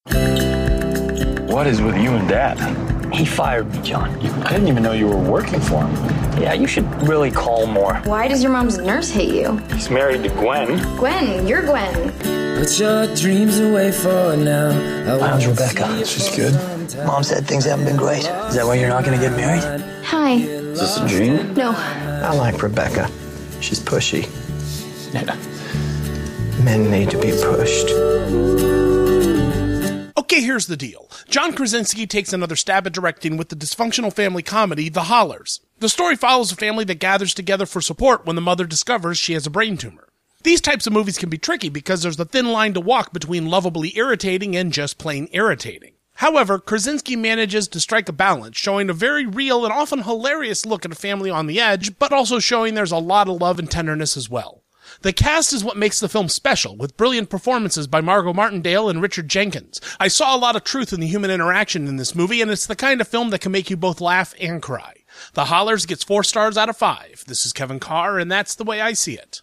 ‘The Hollars’ Radio Review